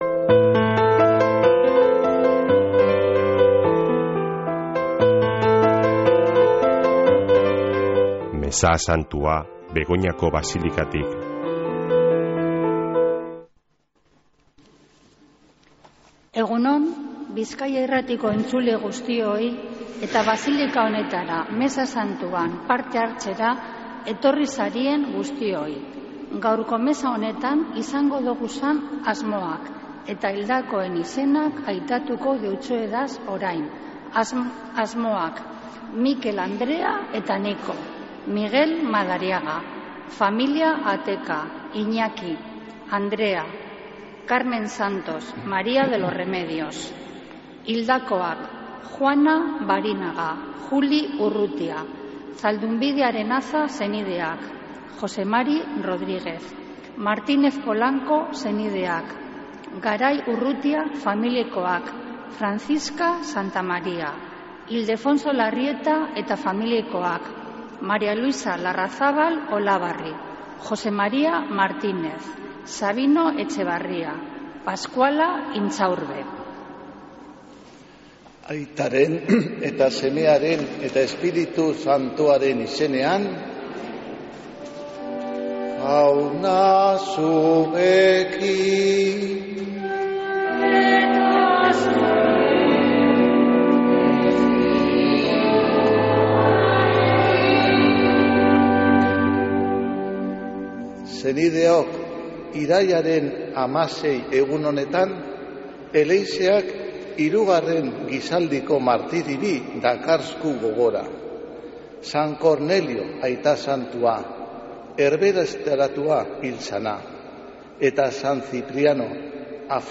Mezea (24-09-16)
Mezea Begoñako basilikatik